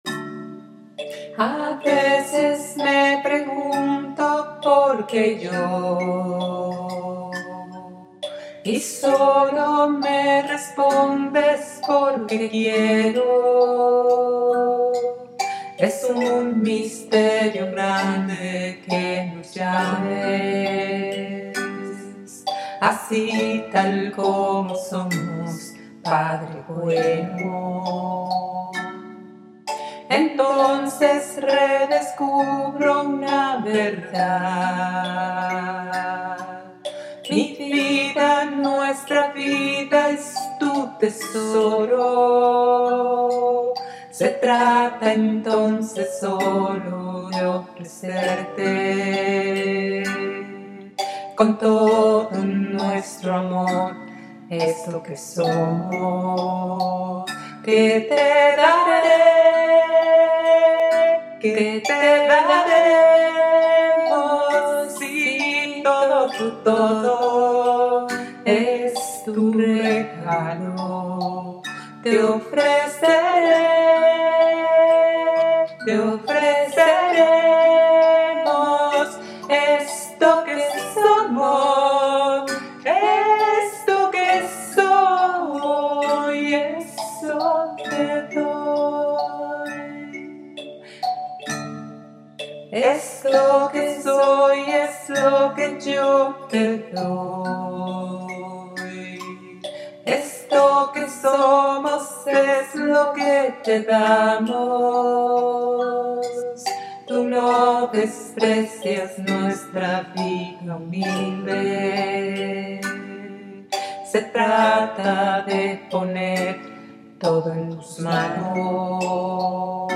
CANCION PARA REFLEXION